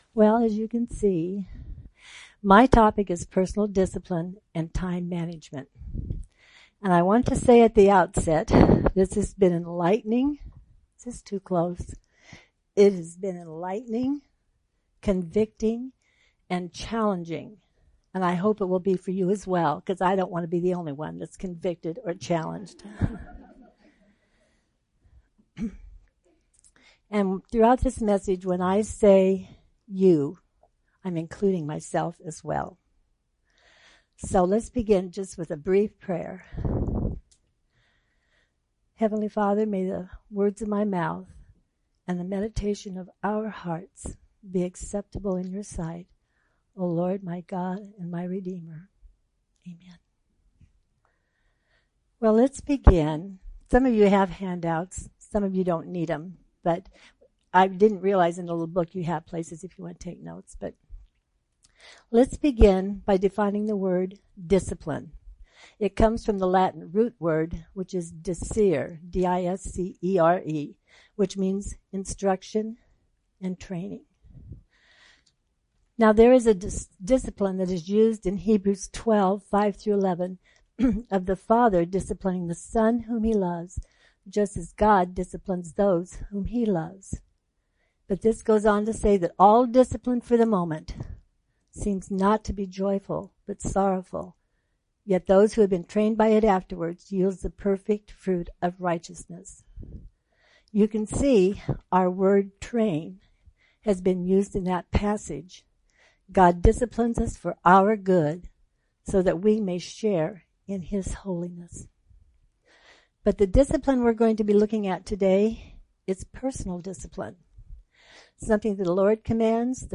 Women Women's Fall Conference - 2024 Audio ◀ Prev Series List Next ▶ Previous 3.